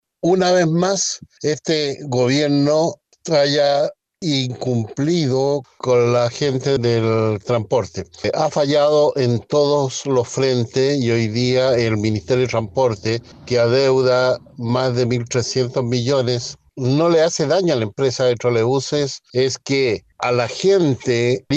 El concejal de la UDI, Dante Iturrieta, manifestó que desde el Ejecutivo han fallado “en todos los frentes”, y dijo que a días del cambio de mando se está formando un problema que tendrá que arrastrar el futuro gobierno.